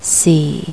Tone: the relative pitch, or variation of pitch, of a syllable
4 LF Low Falling
sh time ®É